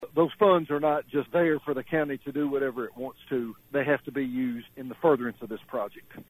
When it comes to spending part of the money allocated for the judicial center on other projects, Judge Alexander said the money must be spent construction of a new judicial center: